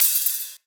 Hats & Cymbals
Cymbal_04.wav